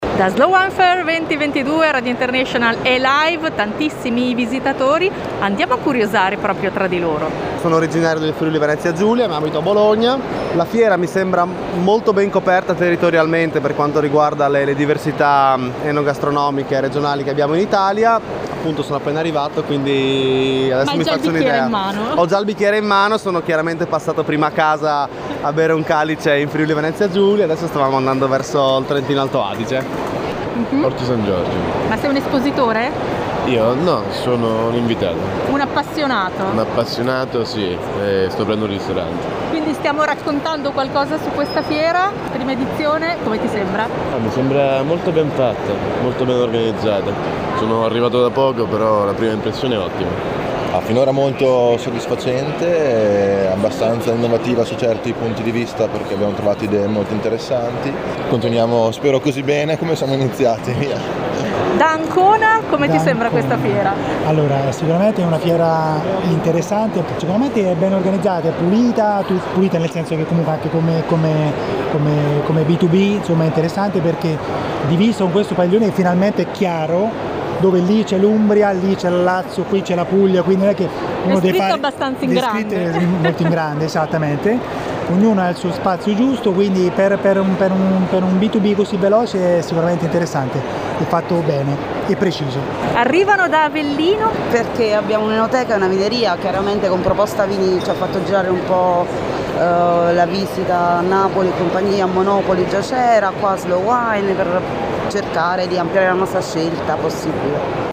Slow Wine Fair – Fiera di Bologna – 27-28-29 Marzo
intervista ai visitatori della Fiera.